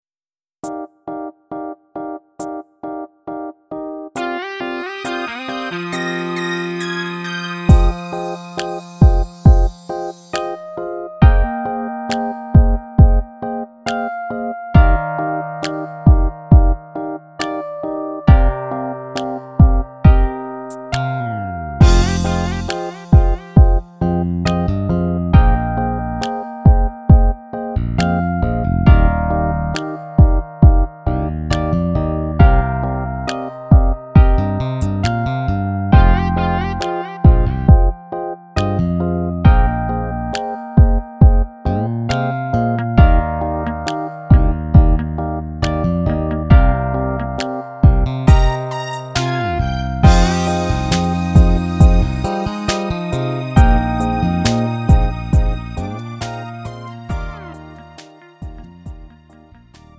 음정 -1키
장르 가요 구분 Lite MR